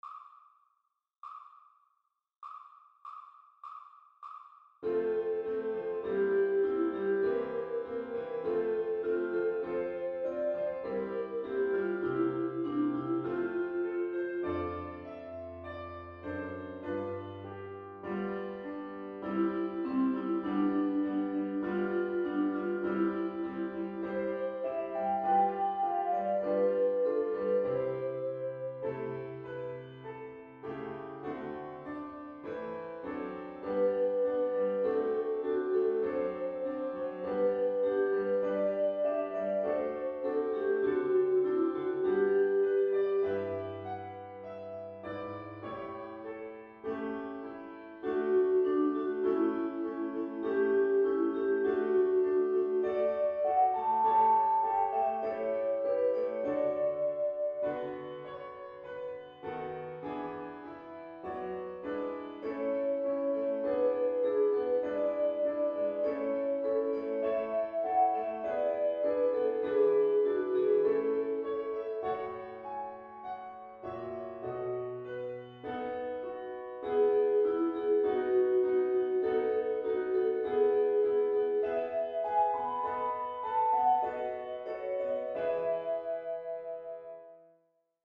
Timpani Tuning MP3 – AXENT
Exercise N.155 (piano) MP3 download